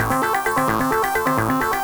Index of /musicradar/8-bit-bonanza-samples/FM Arp Loops
CS_FMArp A_130-A.wav